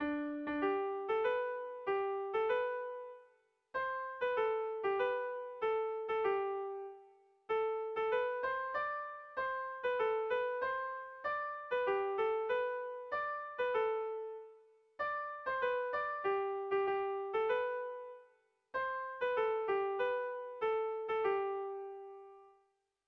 Sentimenduzkoa
ABDEB